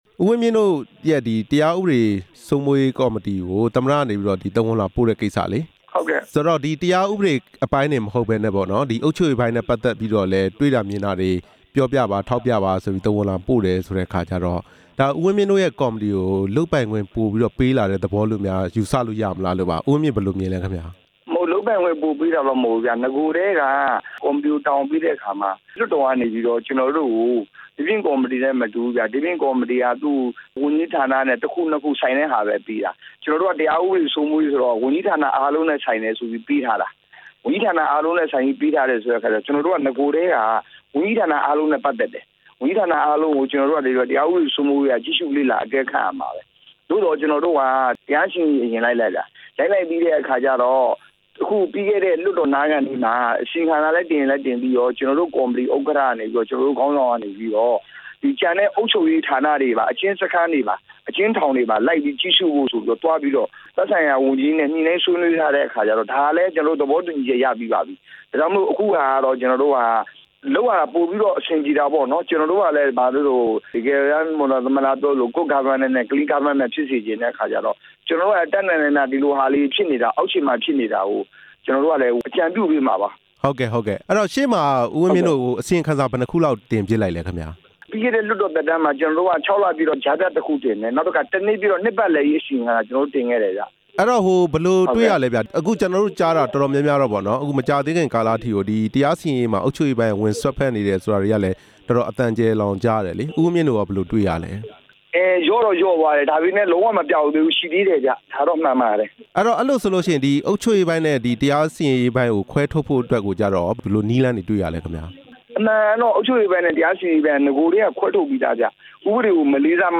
NLD ဗဟိုအလုပ်အမှုဆောင်အဖွဲ့ဝင် ဦးဝင်းမြင့်နဲ့ မေးမြန်းချက်